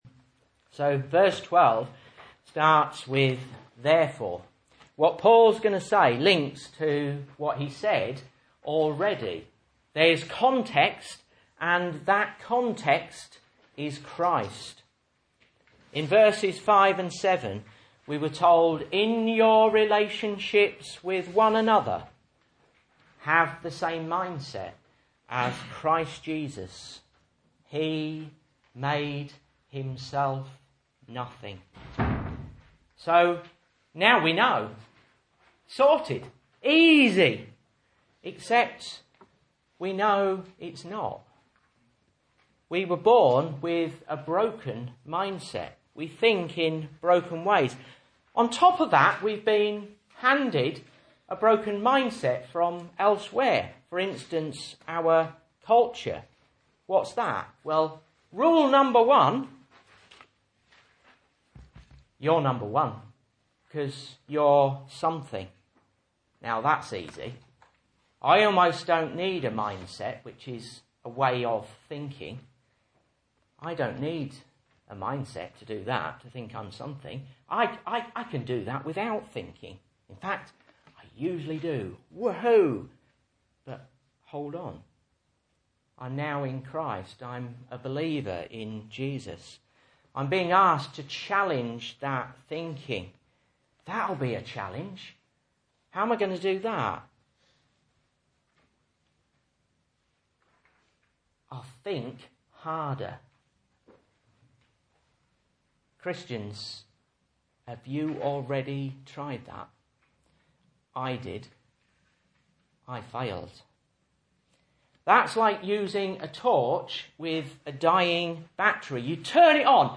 Message Scripture: Philippians 2:12-18 | Listen